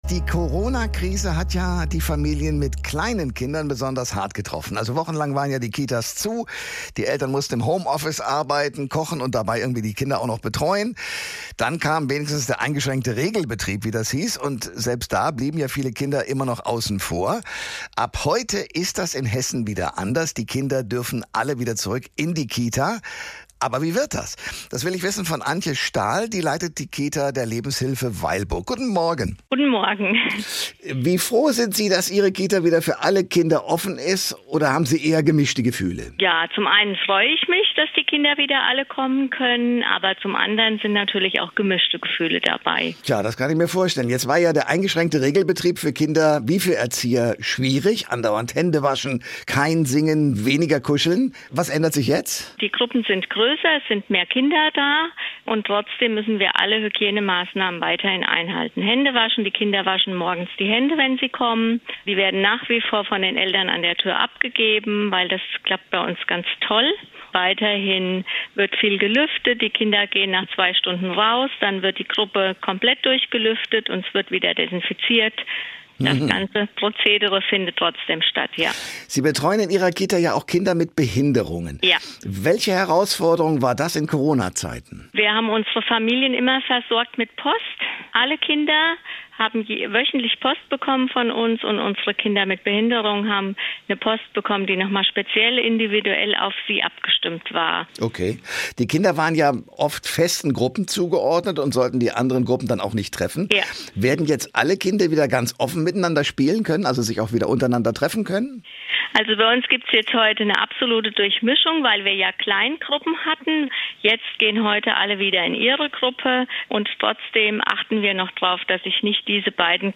Hier finden Sie das hr1-Interview